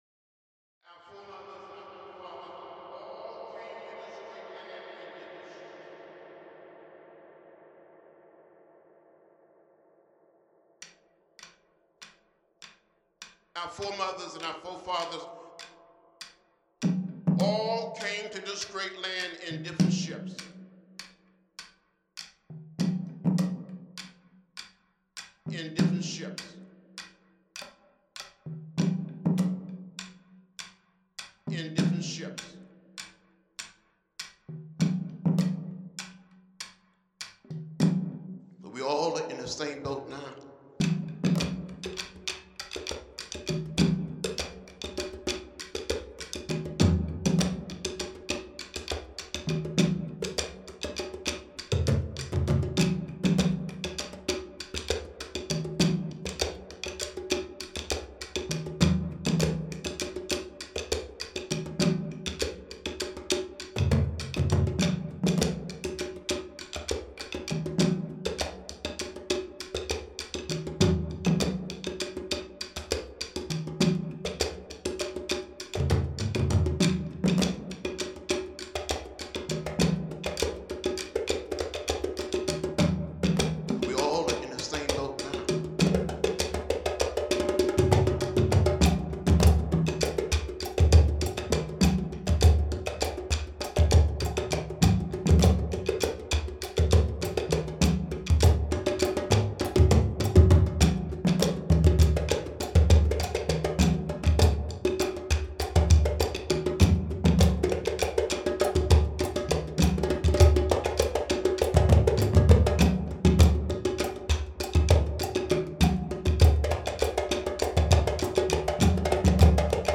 So, this is my first attempt at playing with multiple tracks of similar instruments (dun duns) playing the same or similar parts.
Since this is a diary entry and there’s not a lot of interesting arrangement, I elected to pipe in some recorded moments from speeches of the late, great civil rights icon Rep. John Lewis.